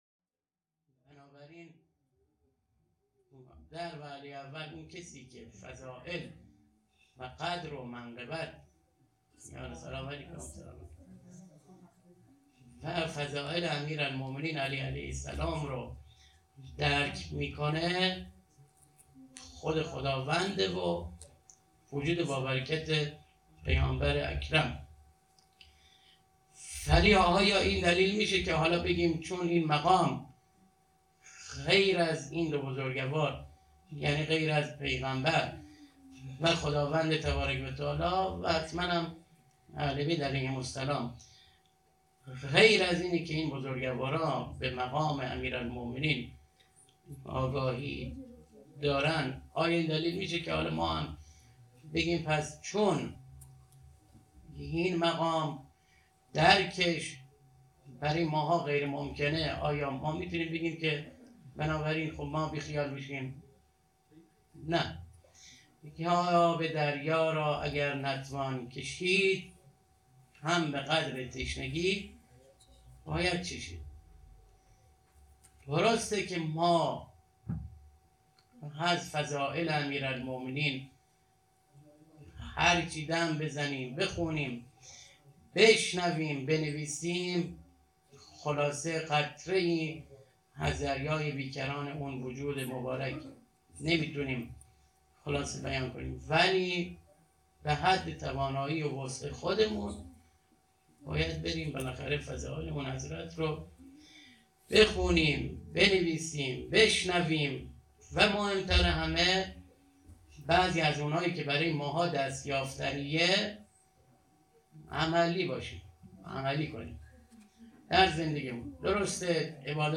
ولادت امام علی(ع)_هیئت میثاق با شهدا